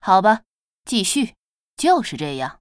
文件 文件历史 文件用途 全域文件用途 Cyrus_amb_04.ogg （Ogg Vorbis声音文件，长度2.6秒，97 kbps，文件大小：31 KB） 源地址:游戏语音 文件历史 点击某个日期/时间查看对应时刻的文件。